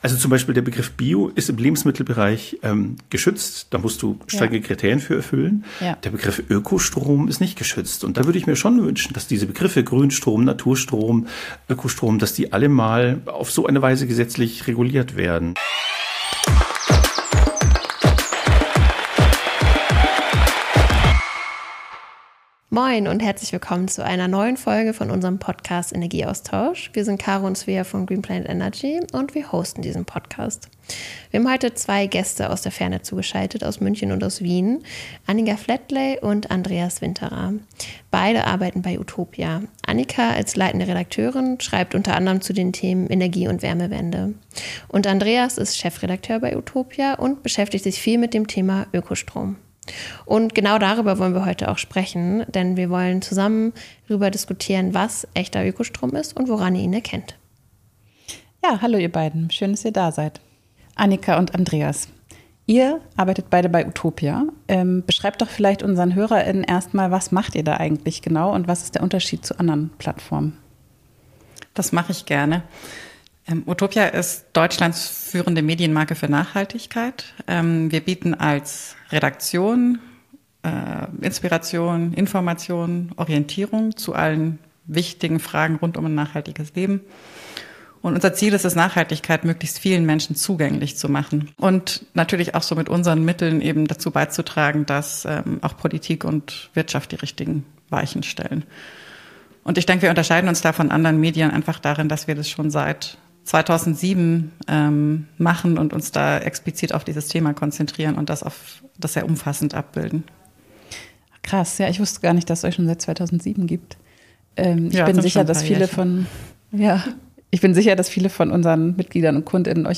Was ist guter Ökostrom? Ein Gespräch mit Utopia ~ Energieaustausch Podcast